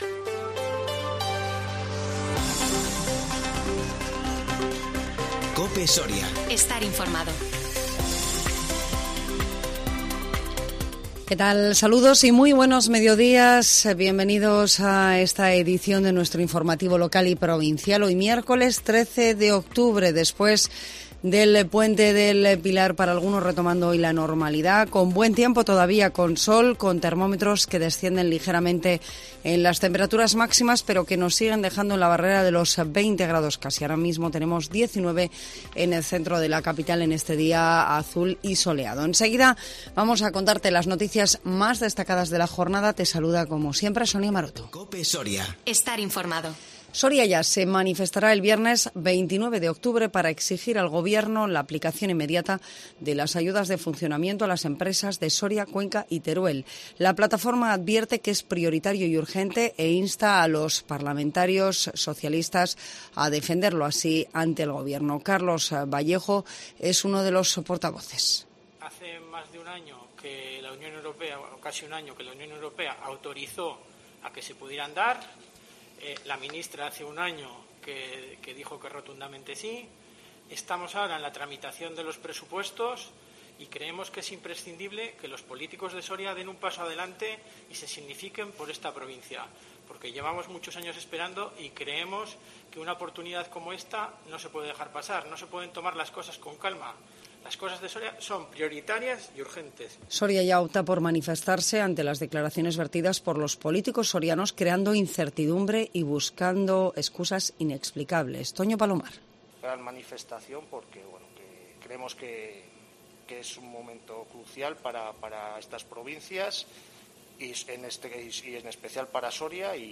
INFORMATIVO MEDIODÍA 13 OCTUBRE 2021